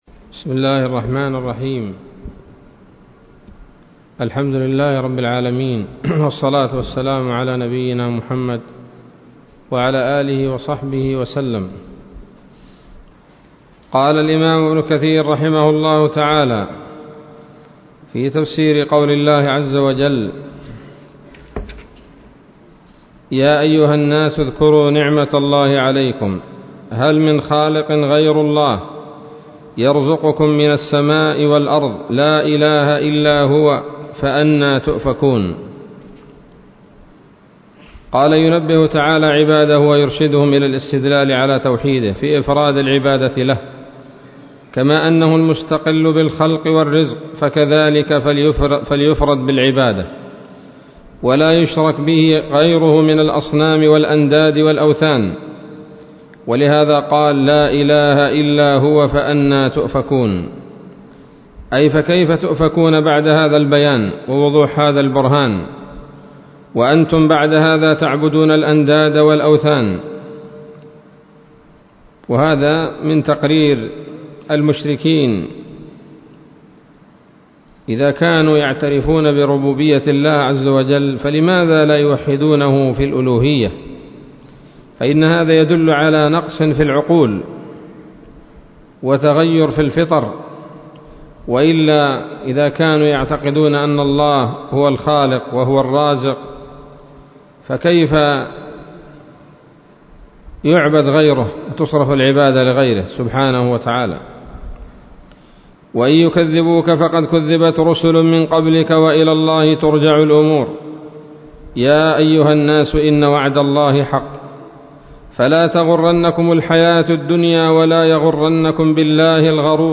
الدرس الثاني من سورة فاطر من تفسير ابن كثير رحمه الله تعالى